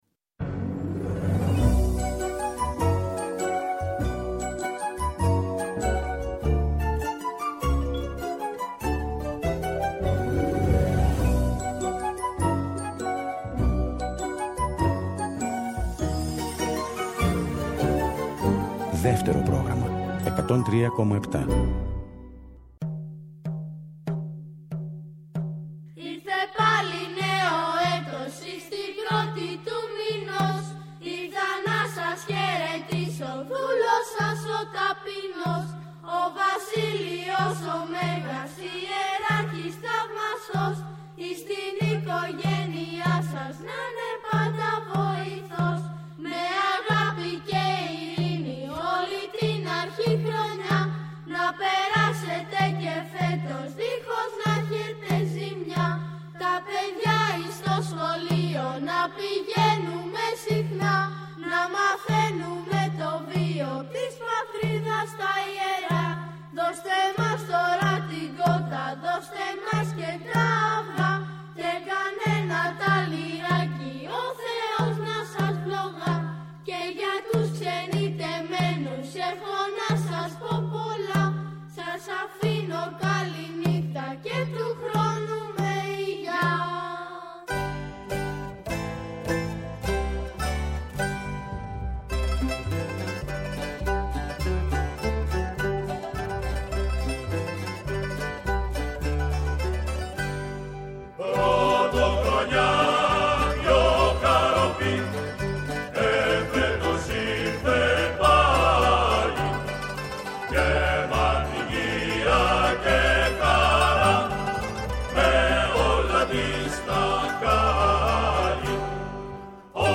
Η παραδοσιακή μας μουσική, το δημοτικό τραγούδι, οι αγαπημένοι μας δημιουργοί από το παρελθόν, αλλά και η νεότερη δραστήρια γενιά των μουσικών, συνθέτουν ένα ωριαίο μουσικό παραδοσιακό γαϊτανάκι.